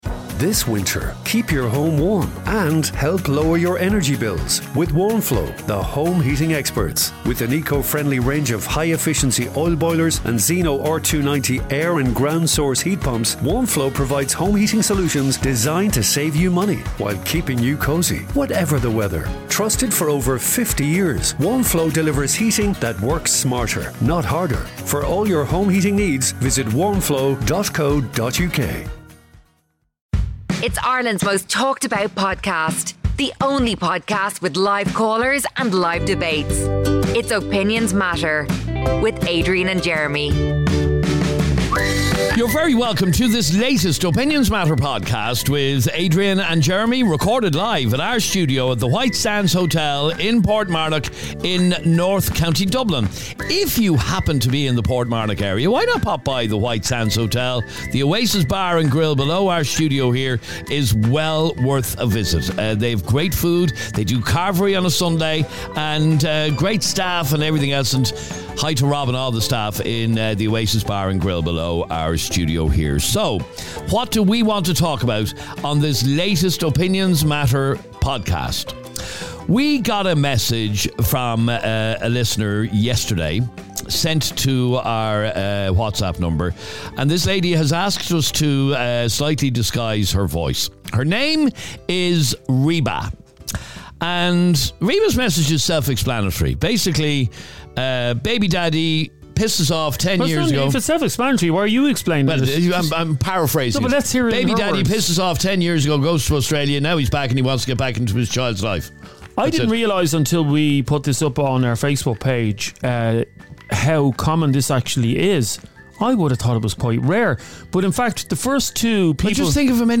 Download - Husband Gets Caught Giving Out About Mother In Law.. Live On Air! | Podbean